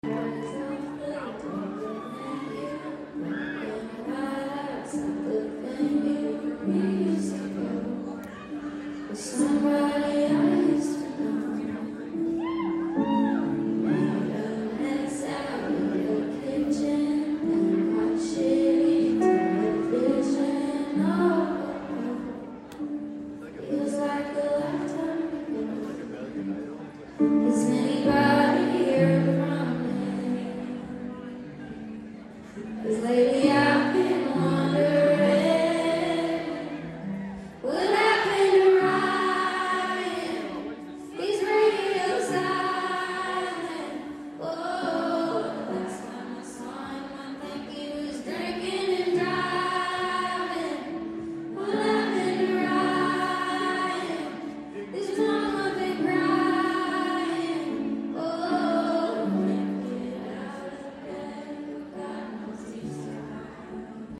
brings a fan on stage to sing